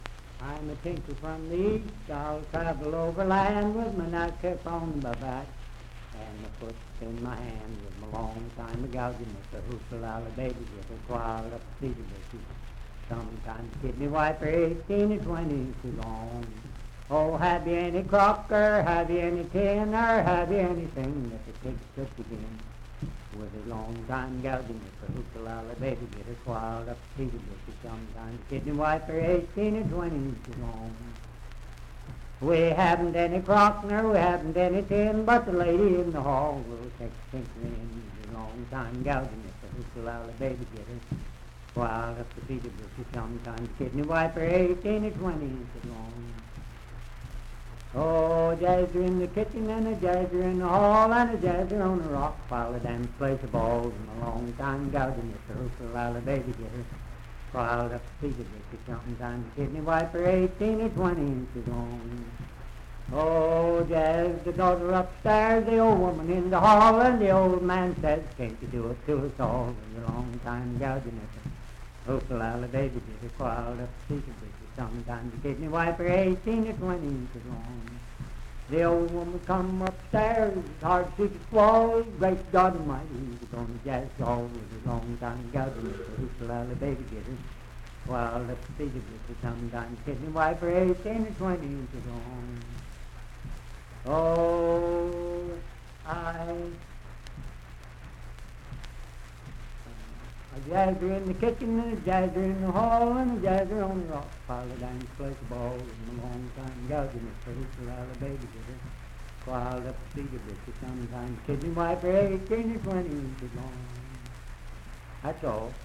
Unaccompanied vocal music
Bawdy Songs
Voice (sung)
Wood County (W. Va.), Parkersburg (W. Va.)